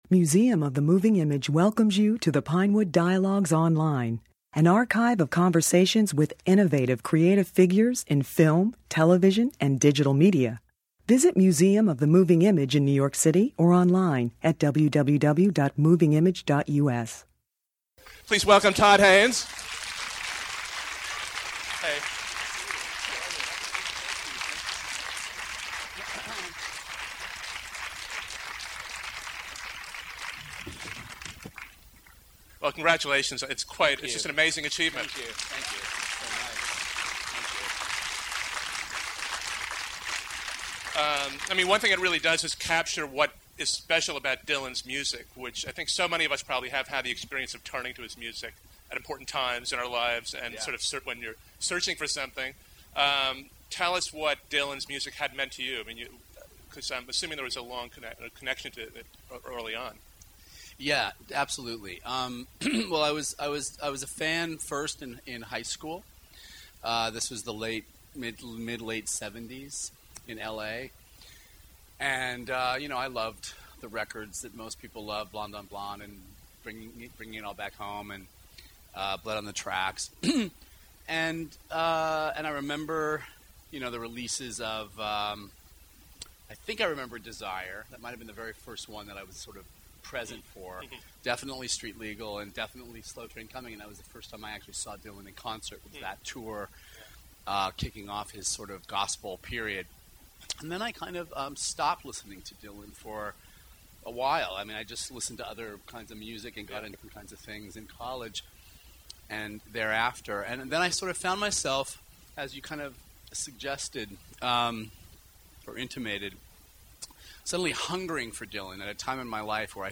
Todd Haynes reinvented the biopic with his movie I'm Not There, a kaleidoscopic portrait of Bob Dylan—and the 1960s—with six different actors, including Cate Blanchett and Richard Gere, playing variations of Dylan. This discussion with Haynes and actor Bruce Greenwood, who plays the journalist "Mr. Jones," demonstrates that Haynes was not just interested in exploring the details of Dylan's life, but of the fundamental concept of identity as a form of performance, a theme central to all of his films.